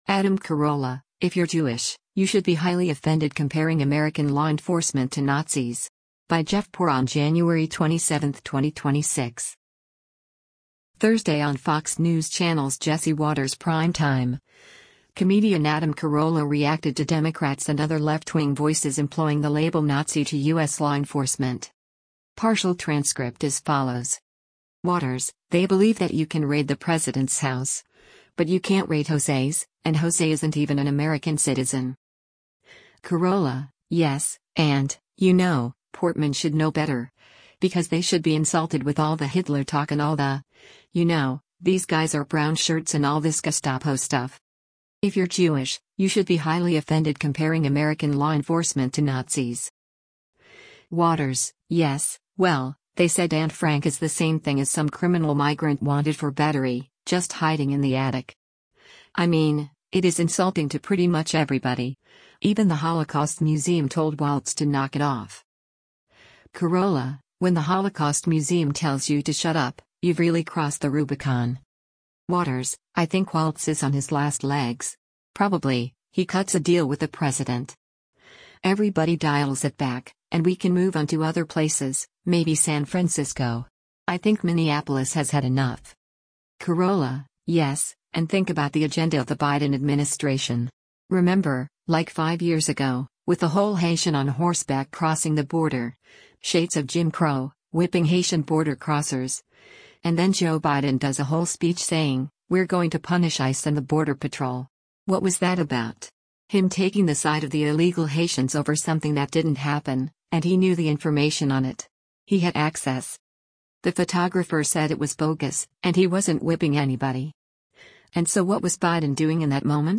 Thursday on Fox News Channel’s “Jesse Watters Primetime,” comedian Adam Carolla reacted to Democrats and other left-wing voices employing the label “Nazi” to U.S. law enforcement.